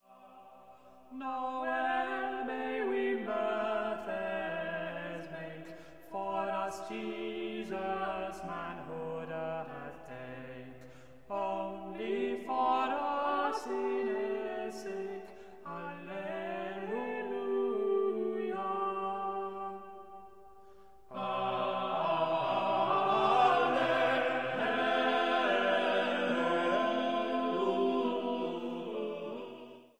Alleluia: Now Well May We Mirthes Make (Middle English Lyric; Christmas Carol)
The Virgin & Christ-Child by Henry's Eight